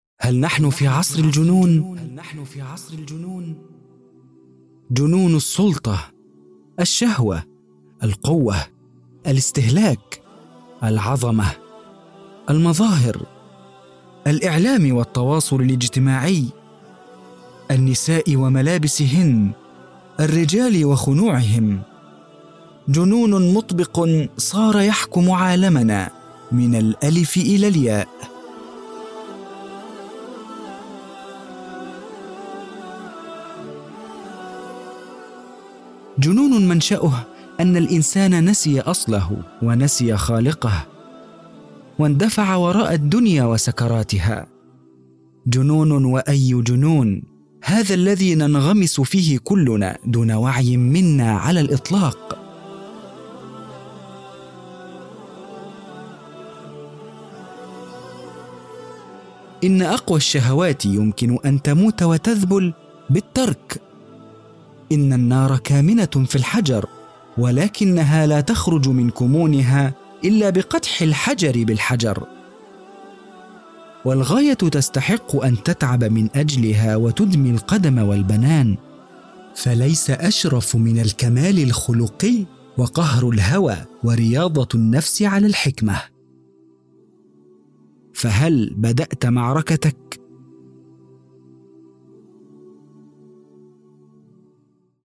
عصر الجنون – نص أدبي
• ذكر
• أصوات شخصيات
• العربية الفصحى
• باريتون Baritone (متوسط العرض)
• في منتصف العمر ٣٥-٥٥